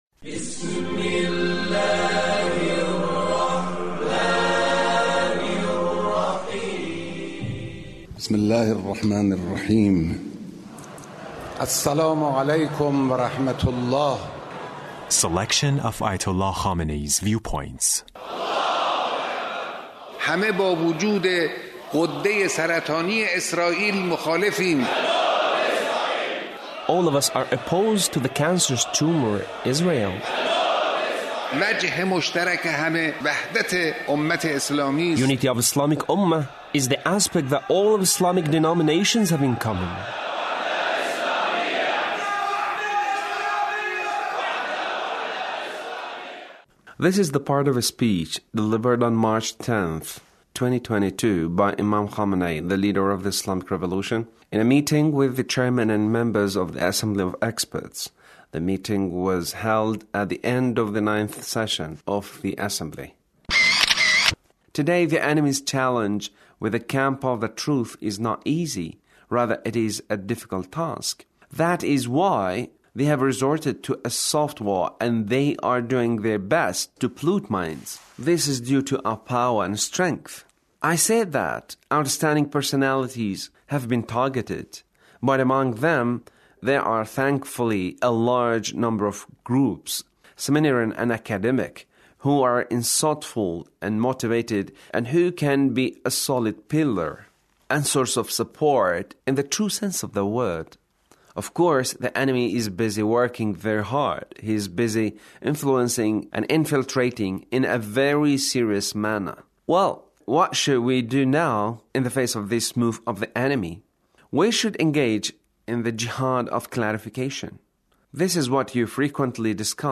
Leader's speech (1363)
The Leader's speech on Assembly of Experts